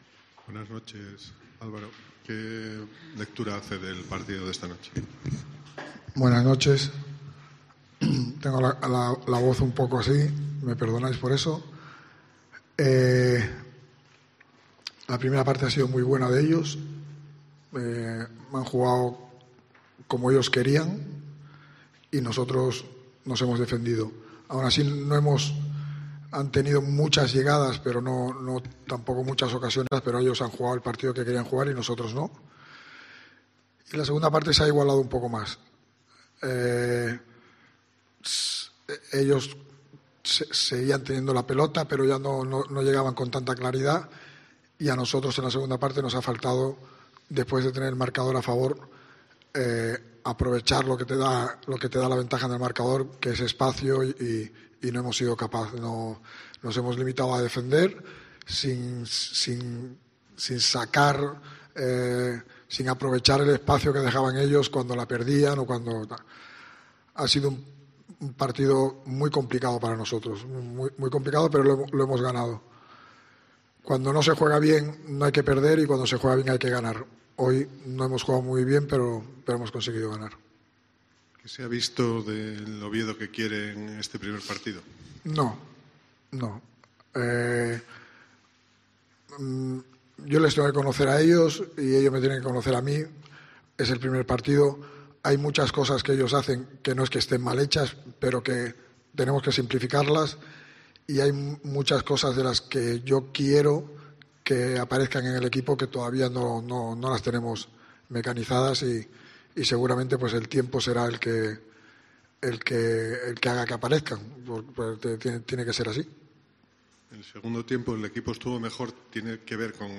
Rueda de prensa de Álvaro Cervera (post Málaga)